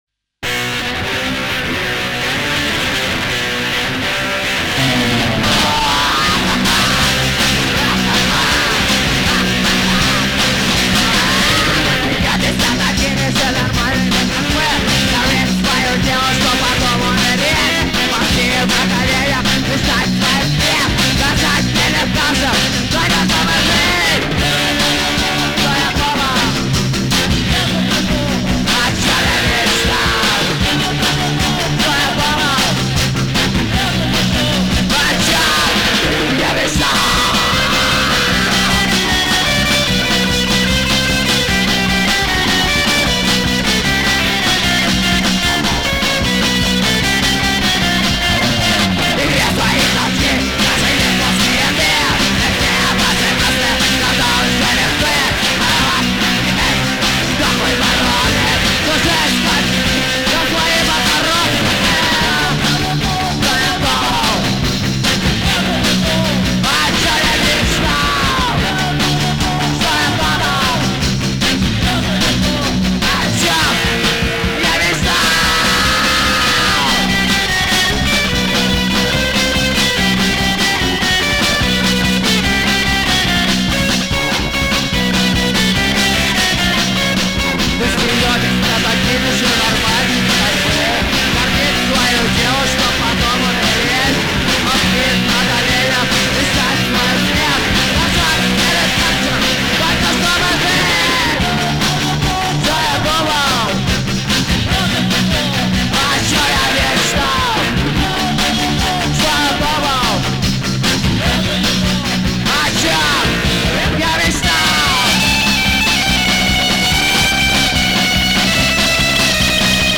Страница содержит различную панк музыку!